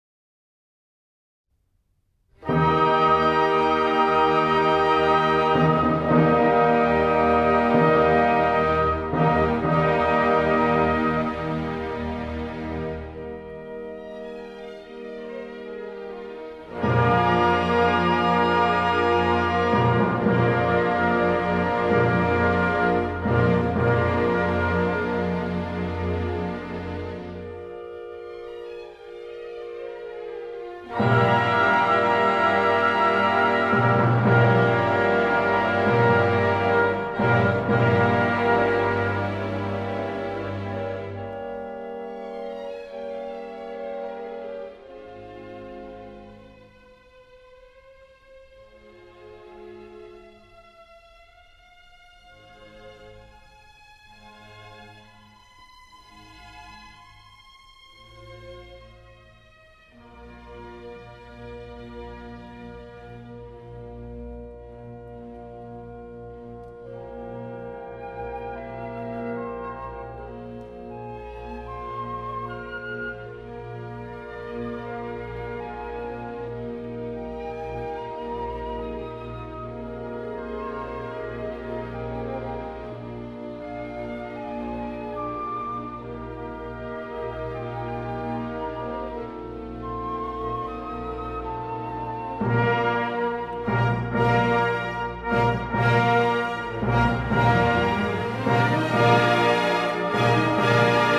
Eighth note = 72-74